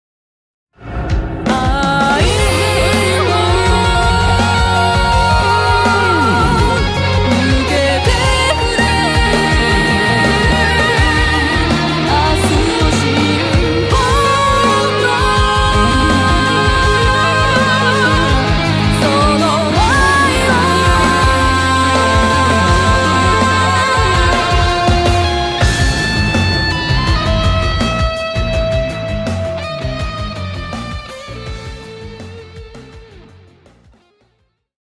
キャラクターイメージソング